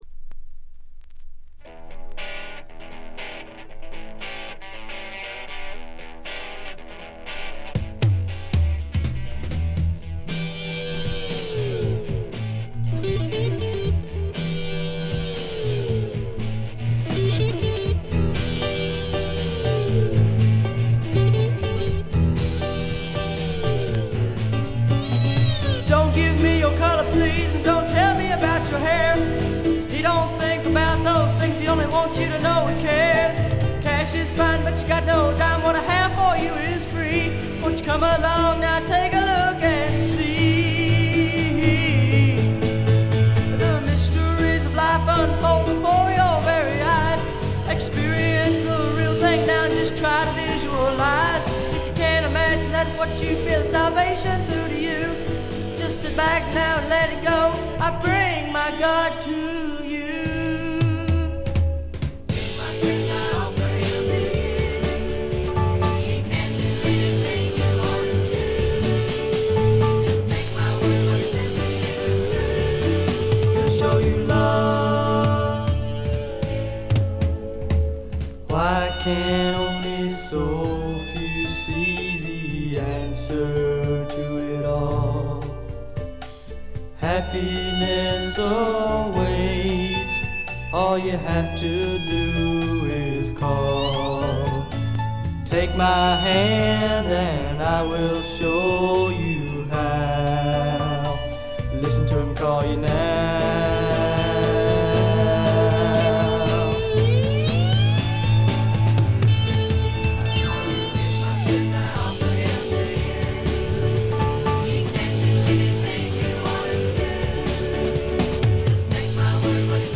ところどころでチリプチ、散発的なプツ音少々。
試聴曲は現品からの取り込み音源です。